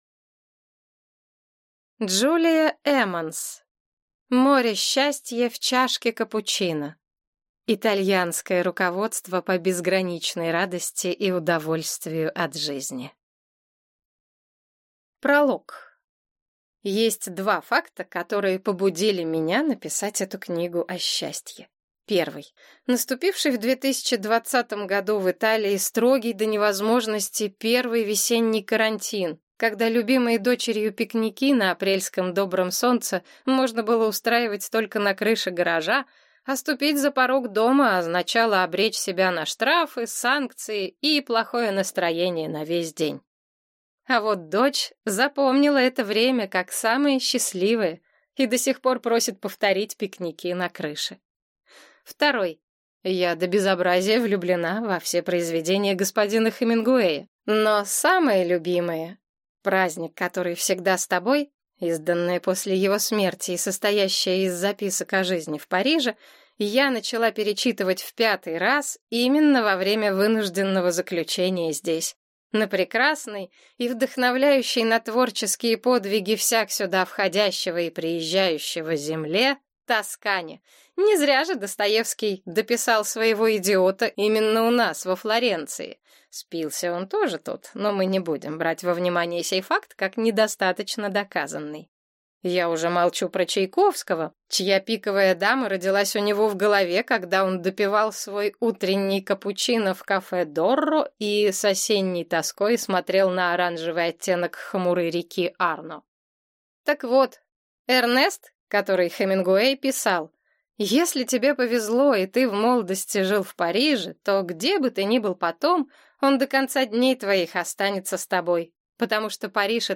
Аудиокнига Море счастья в чашке капучино. Итальянское руководство по безграничной радости и удовольствию от жизни | Библиотека аудиокниг